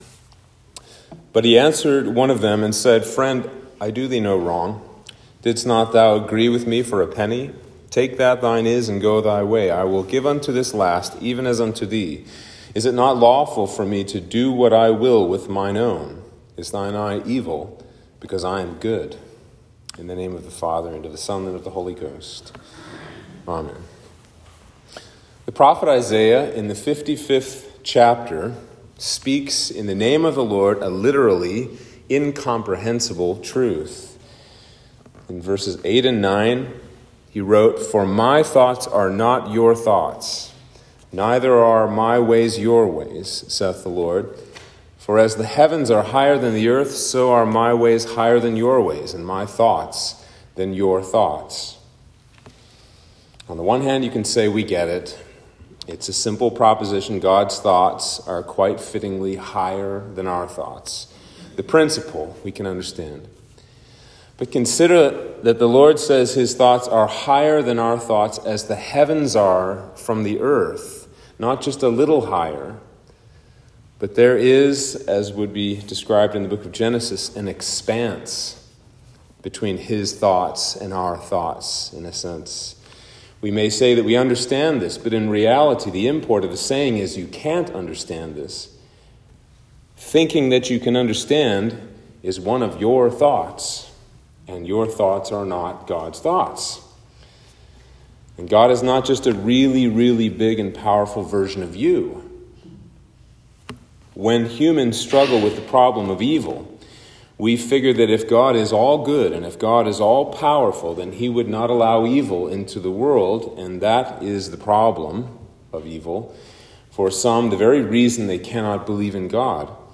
Sermon for Septuagesima